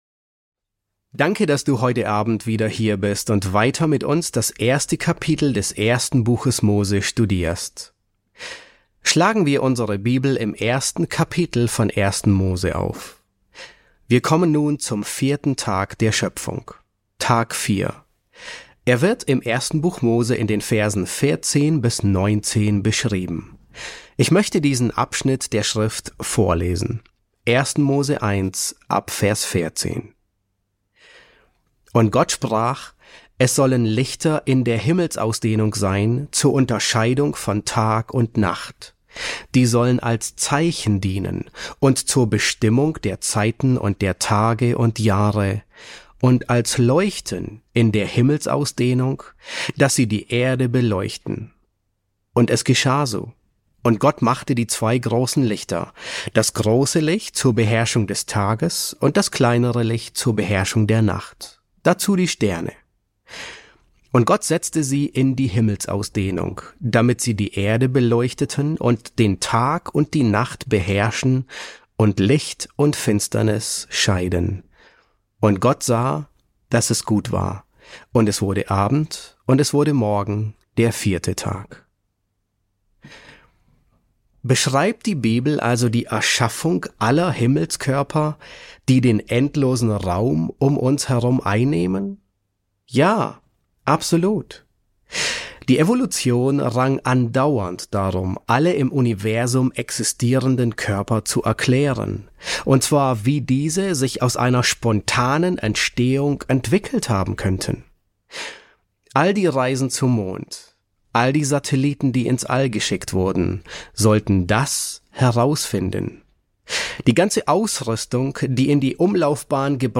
E8 S6 | Der 4. Schöpfungstag ~ John MacArthur Predigten auf Deutsch Podcast